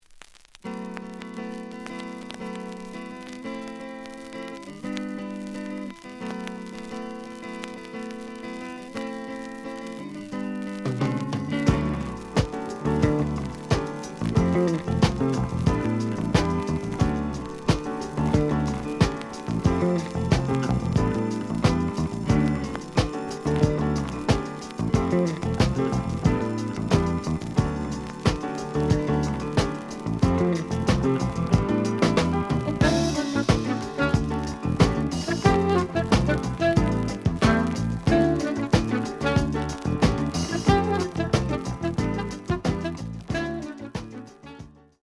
The audio sample is recorded from the actual item.
●Genre: Disco
Some noise on B side.)